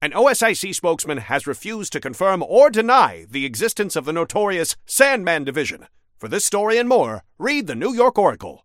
[[Category:Newscaster voicelines]]
Newscaster_headline_58.mp3